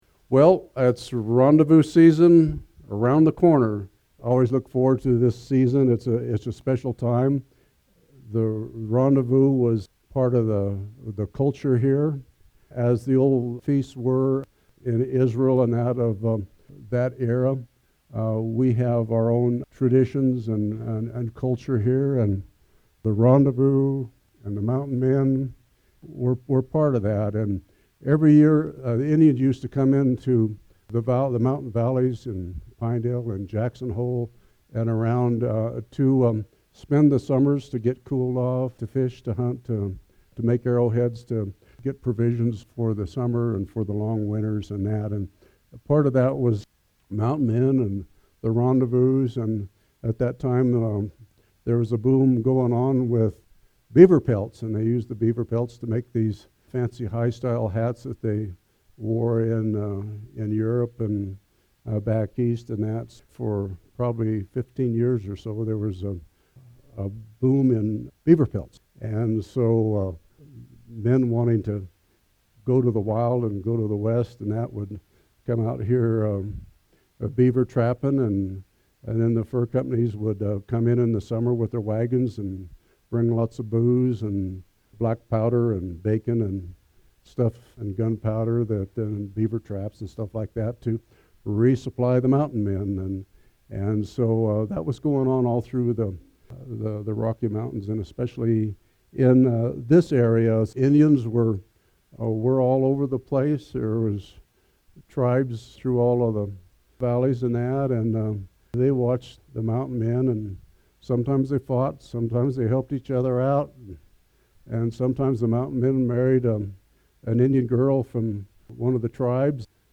SERMON: Historical overview of Sublette County history – Church of the Resurrection